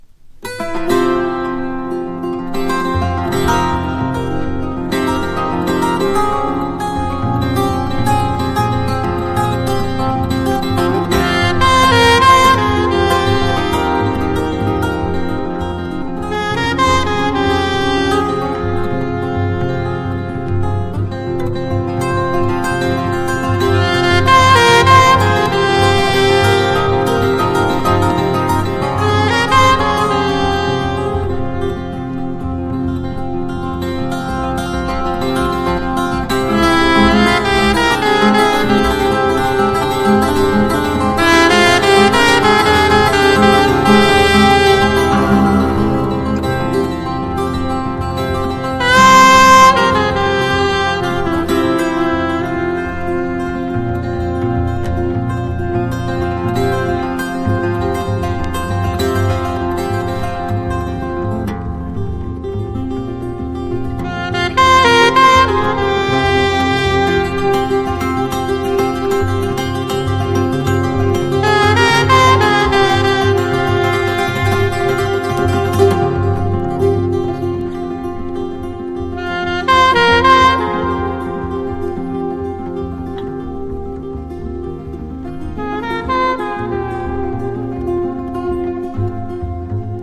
JAZZ ROCK# MODERN JAZZ# FREE